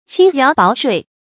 輕徭薄稅 注音： ㄑㄧㄥ ㄧㄠˊ ㄅㄠˊ ㄕㄨㄟˋ 讀音讀法： 意思解釋： 猶言輕徭薄賦。